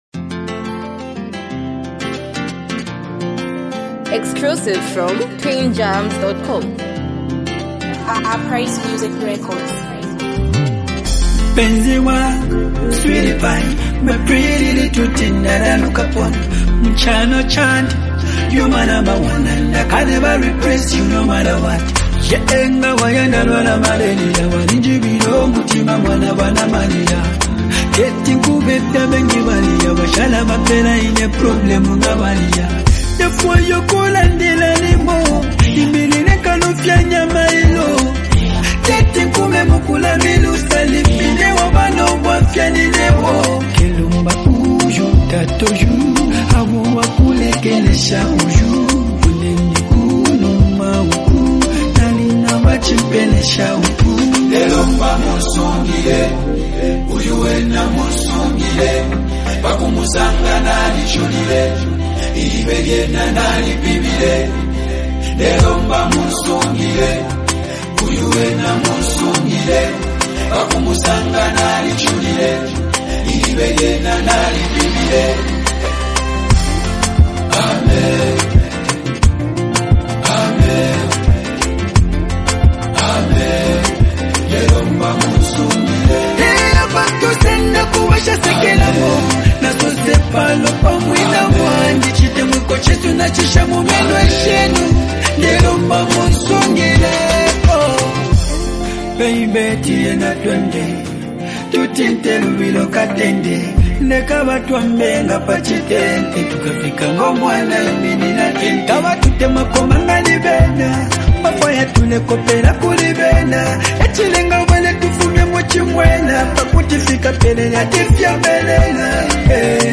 calm, soulful instrumental